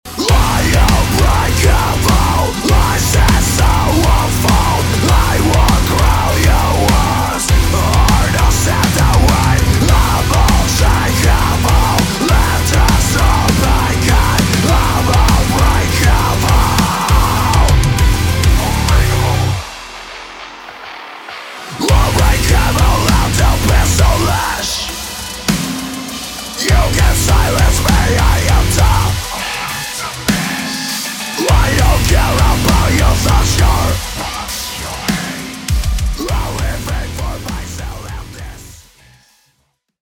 Género: Alternative / Metalcore.